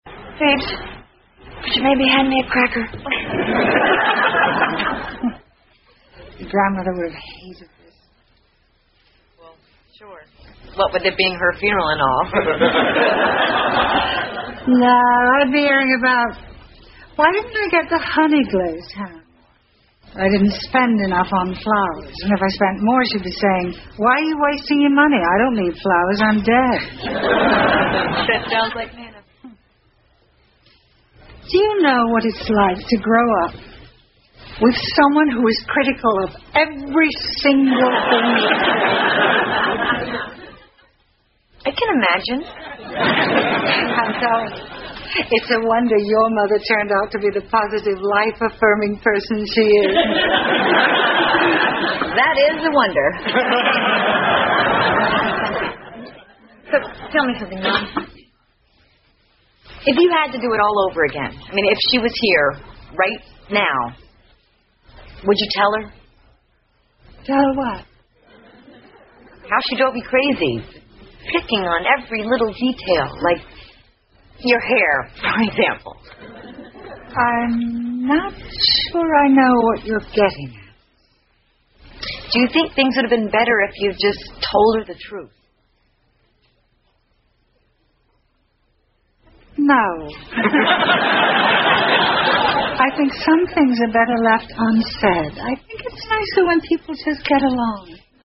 在线英语听力室老友记精校版第1季 第97期:祖母死了两回(11)的听力文件下载, 《老友记精校版》是美国乃至全世界最受欢迎的情景喜剧，一共拍摄了10季，以其幽默的对白和与现实生活的贴近吸引了无数的观众，精校版栏目搭配高音质音频与同步双语字幕，是练习提升英语听力水平，积累英语知识的好帮手。